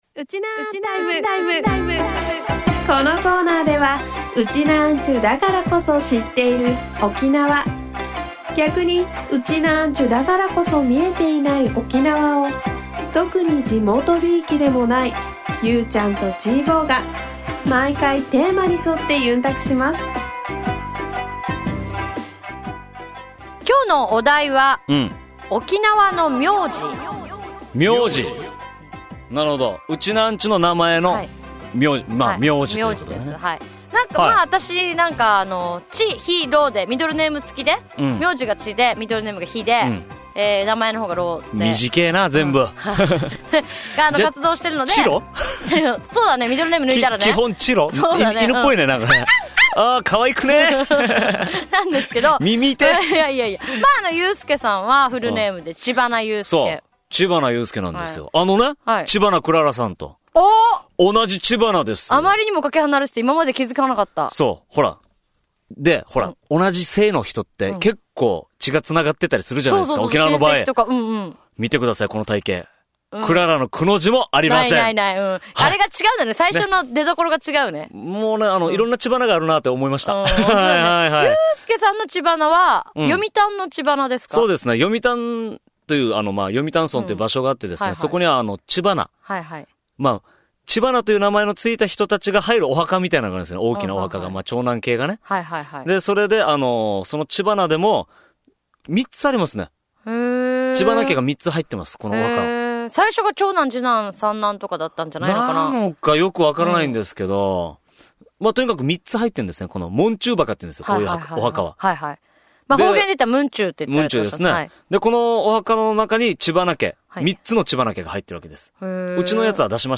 沖縄から全世界へ配信しているインターネット・ラジオ